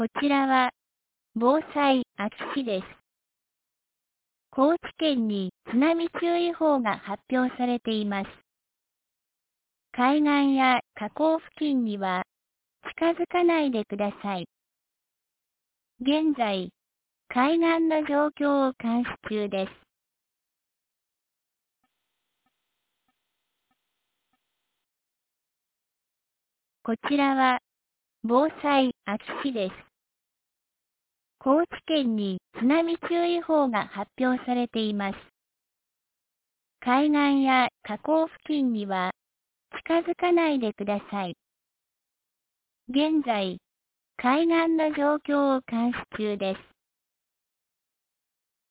2023年10月09日 08時25分に、安芸市より全地区へ放送がありました。